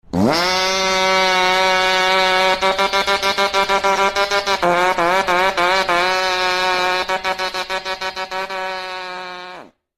Car Fart Sound Button - Free Download & Play